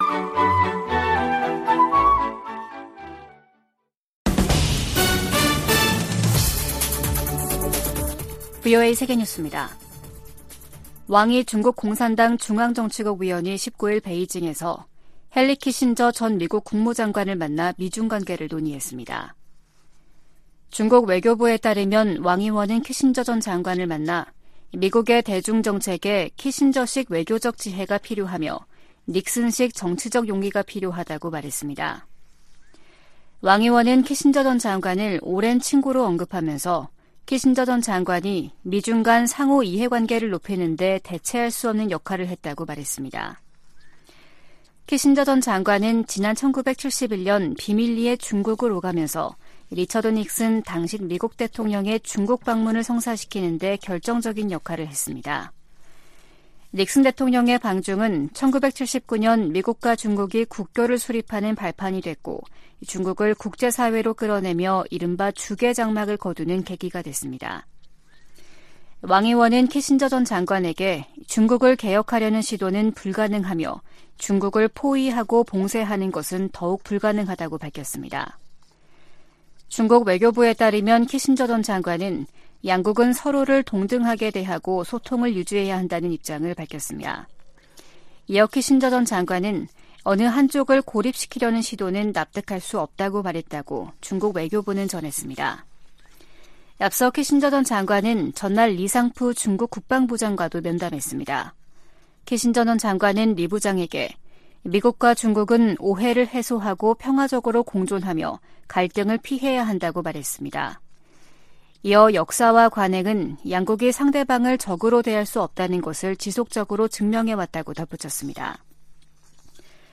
VOA 한국어 아침 뉴스 프로그램 '워싱턴 뉴스 광장' 2023년 7월 20일 방송입니다. 로이드 오스틴 미 국방장관이 판문점 공동경비구역을 견학하던 미군의 월북을 확인했습니다.